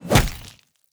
Axe Body Hit.wav